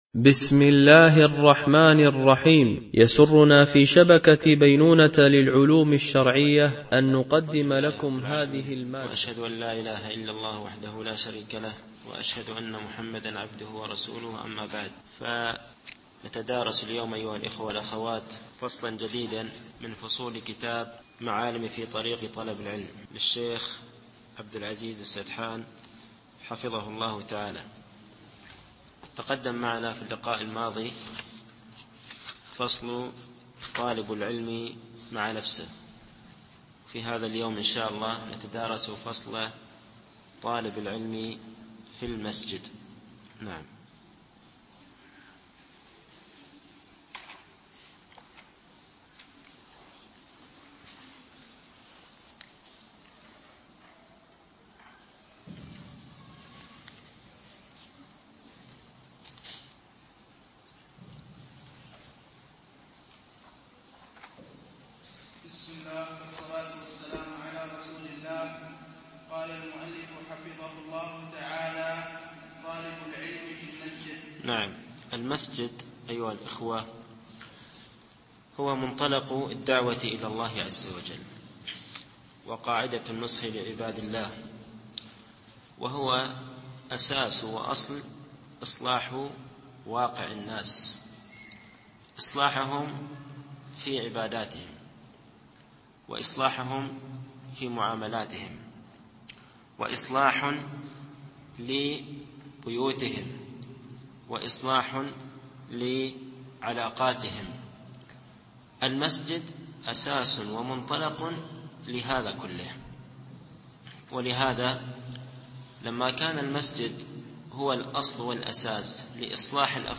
التعليق على كتاب معالم في طريق طلب العلم (طالب العلم في مسجده) - الدرس الأول
MP3 Mono 22kHz 32Kbps (CBR)